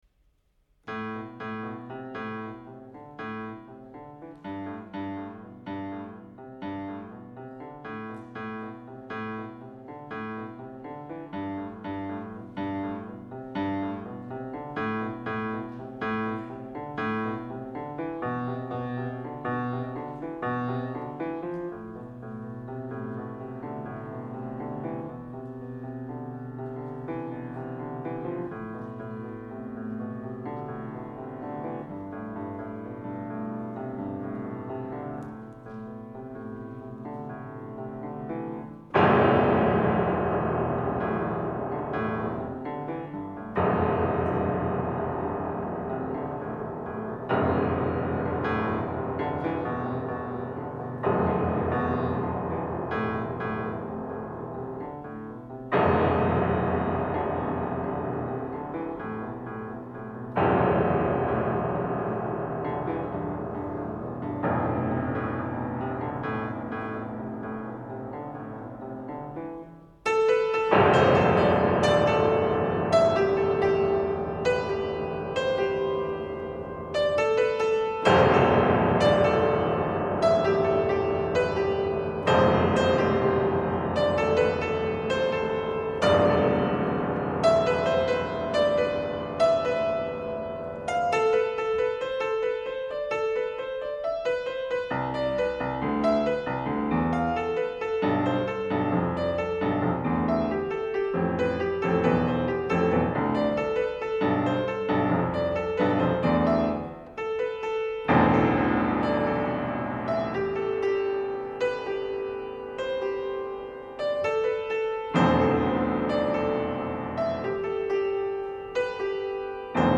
This recording is from that concert.